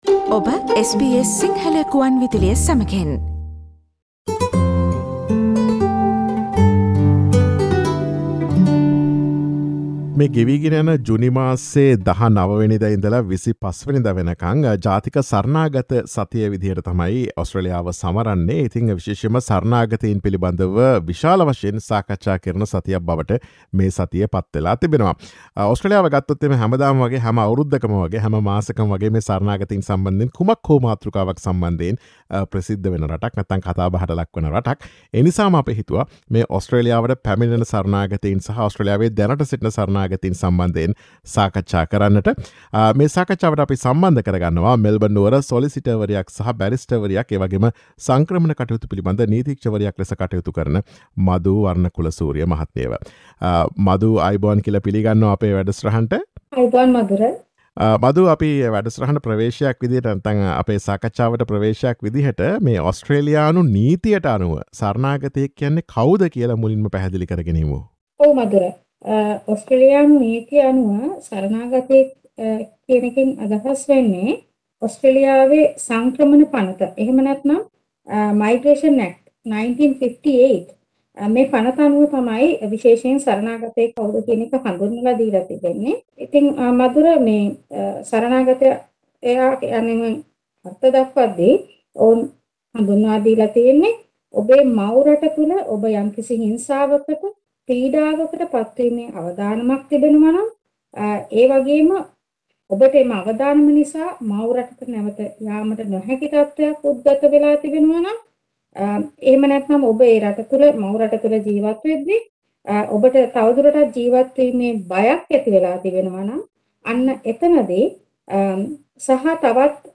ජුනි 19 - 25 ජාතික සරණාගත සතිය නිමිතිකොටගෙන සරණාගතයෙක් ලෙස බාරගැනීමට ඔස්ට්‍රේලියාව සලකනු ලබන ප්‍රධාන අවශ්‍යතා සහ සාධක මෙන්ම නීති විරෝධී යාත්‍රාවලින් පැමිණෙන පුද්ගලයින්ට සරණාගත වීසා තවමත් ලබාදෙනවාද යන්න පිළිබඳ SBS සිංහල ගුවන් විදුලිය සිදුකළ සාකච්ඡාවට සවන් දෙන්න.